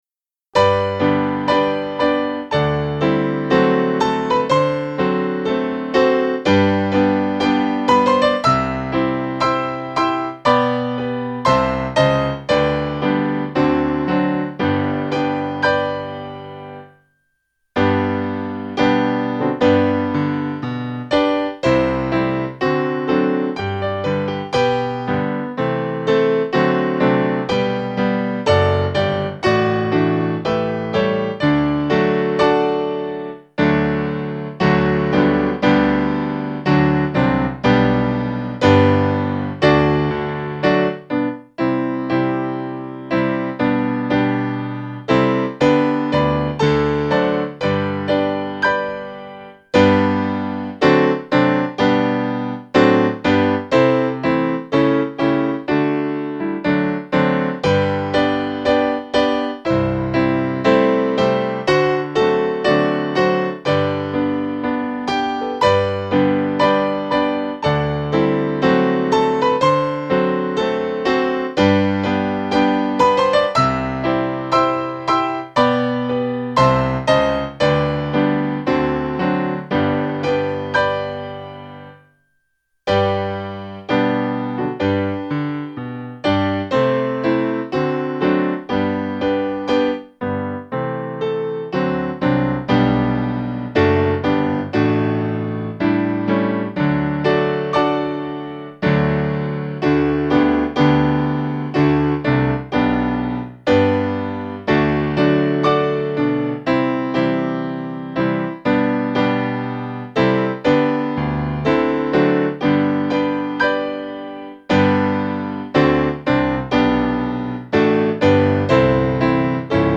AndCanItBe_Piano.mp3